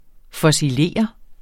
Udtale [ fʌsiˈleˀʌ ]